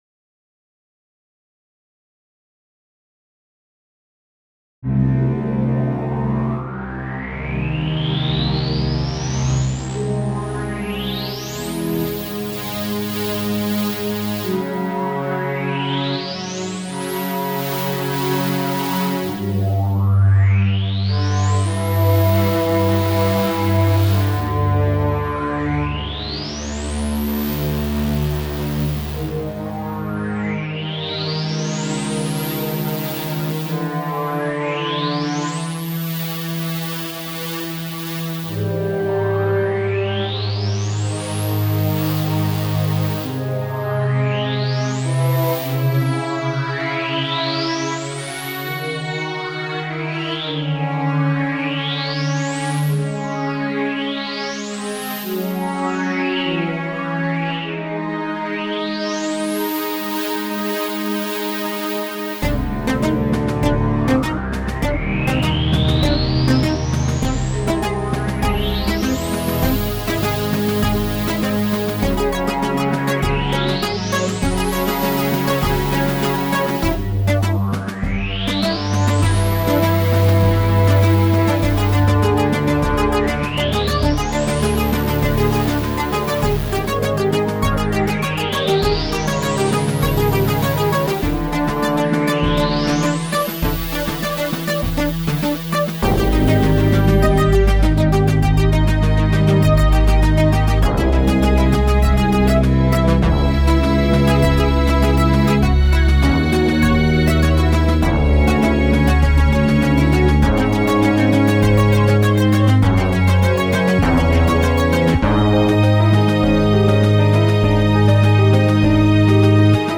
但考虑这个率的个性和特性，所以我要以12平均率去代表。
bass:CC#DD#EF#G G#